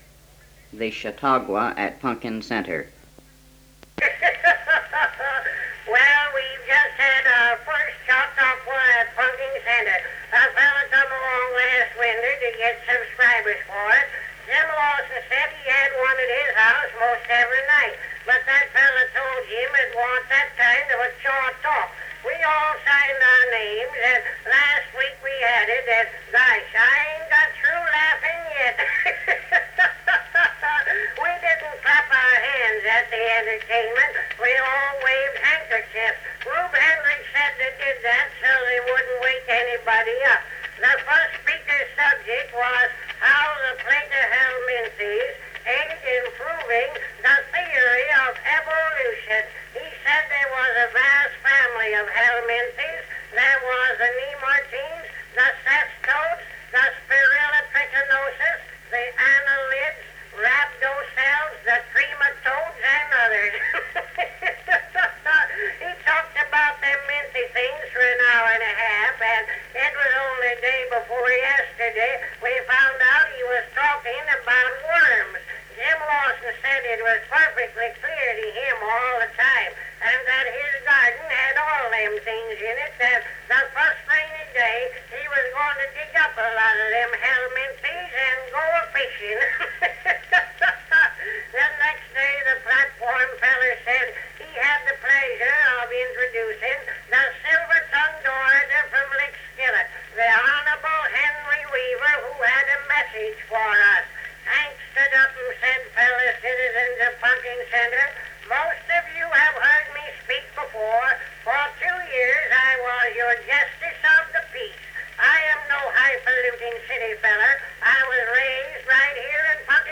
Cal Stewart's comedic routine, The Chautauqua at Punkin Center..